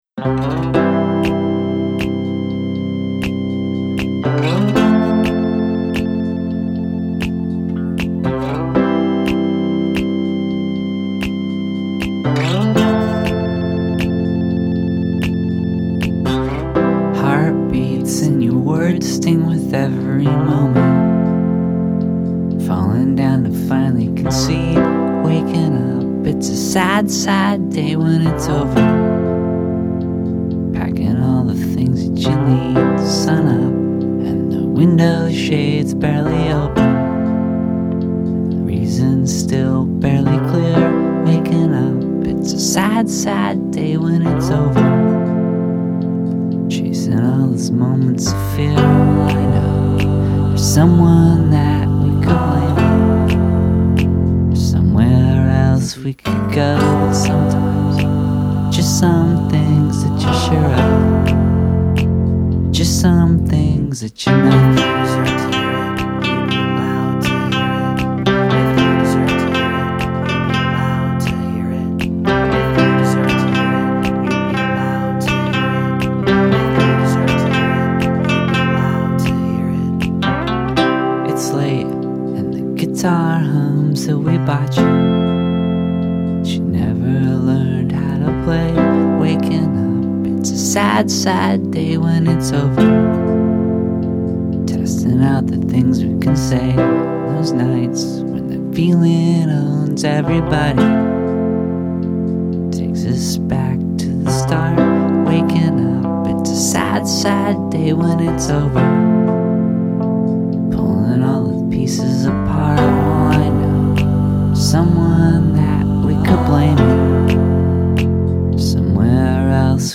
a track recorded during the studio sessions
accordion